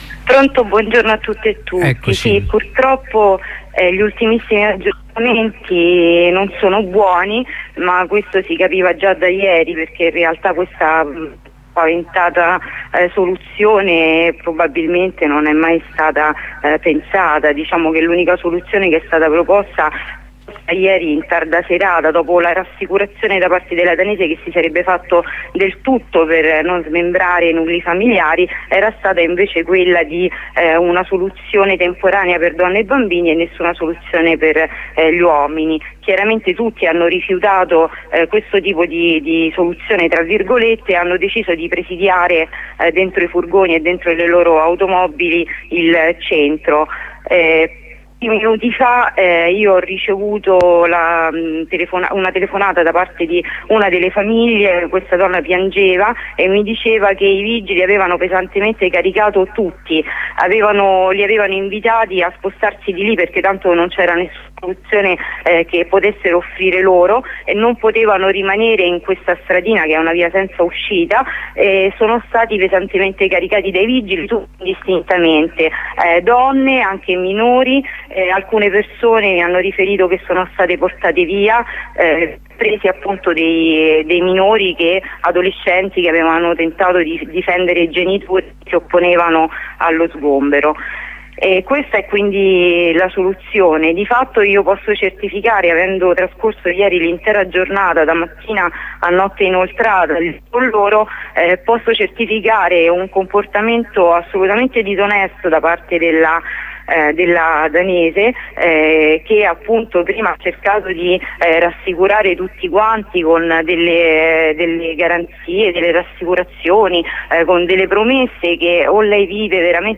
Cariche da parte della polizia municipale nei confronti delle persone che da ieri si trovano in strada dopo l'incendio nel centro di via Amarilli, alla rustica. La testimonianza di un'ascoltatrice